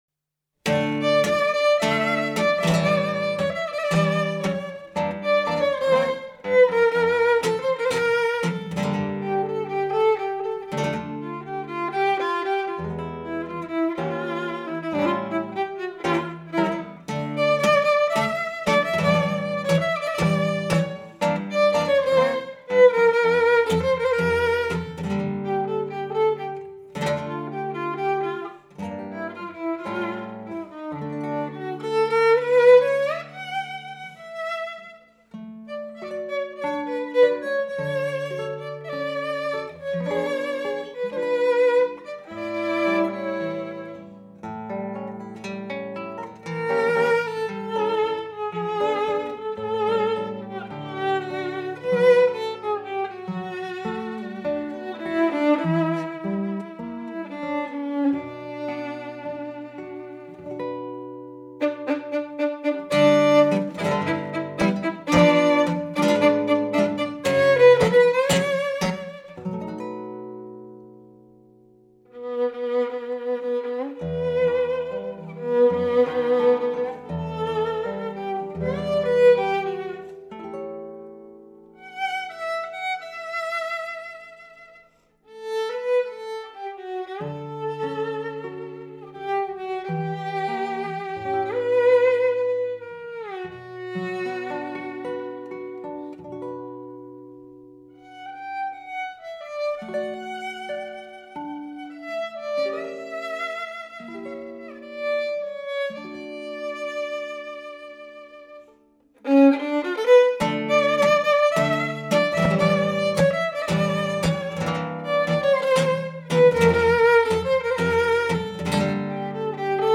Music from South America and Spain.
Percussion.
Category: Latin